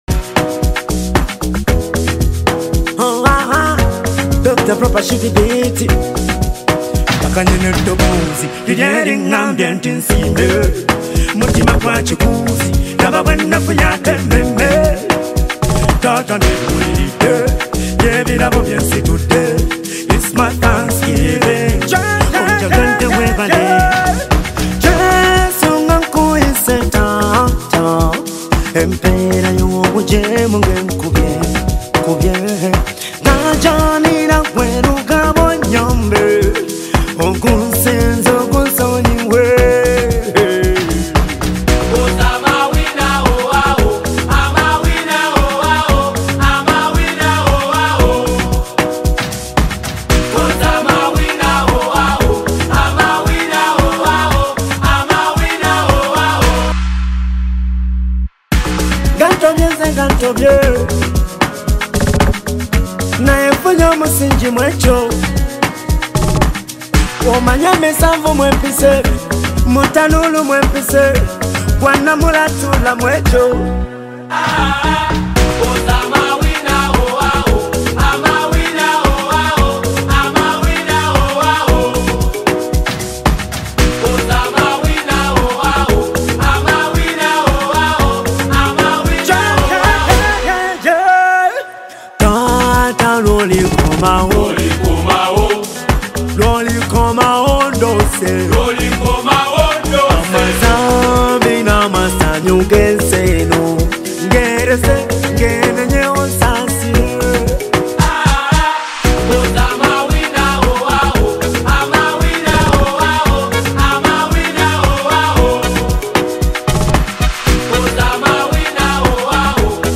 Genre: Gospel Music